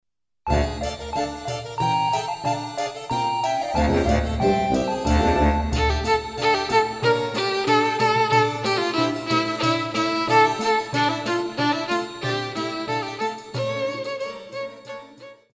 Pop
Violin
Band
Instrumental
World Music
Only backing